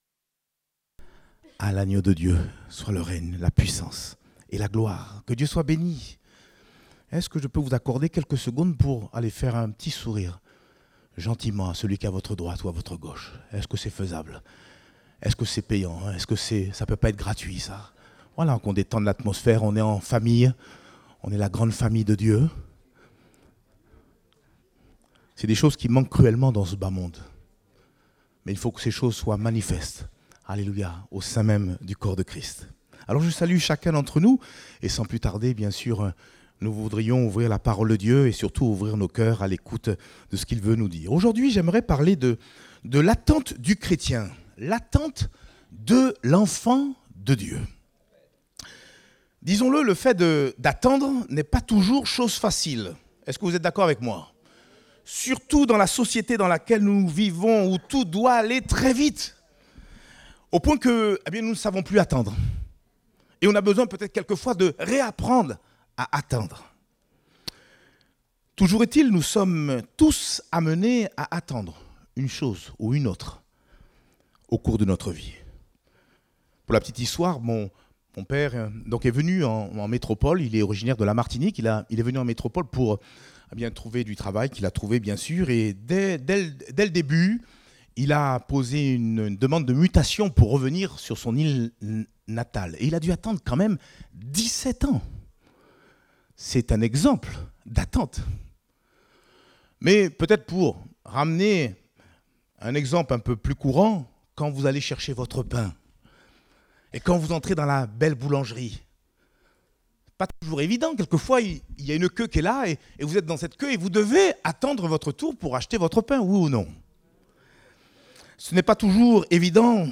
Date : 14 avril 2024 (Culte Dominical)